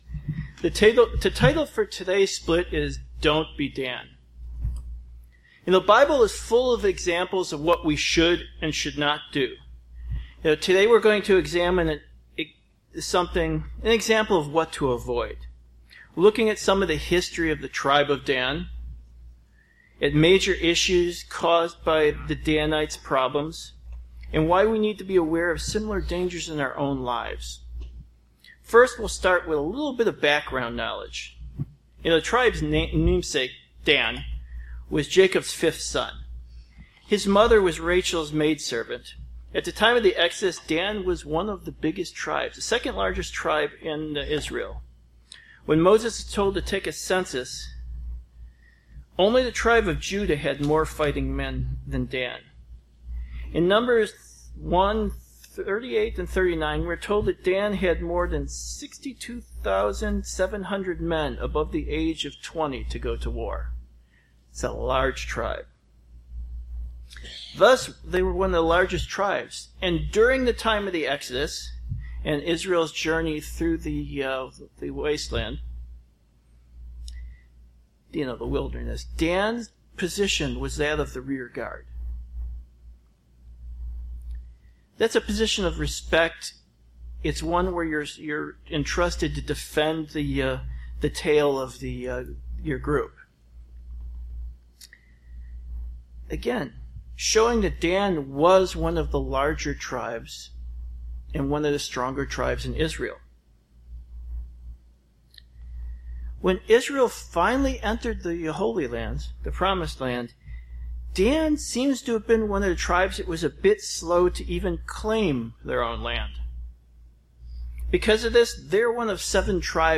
Sermons
Given in Beloit, WI